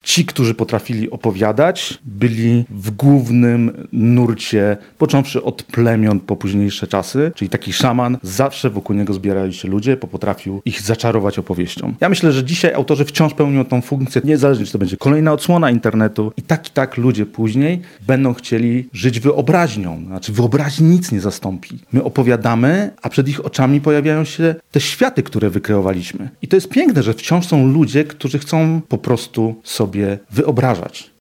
Cała rozmowa na naszym kanale YouTube: Spotkanie z czytelnikami odbyło się w studiu imienia Budki Suflera w siedzibie Radia Lublin.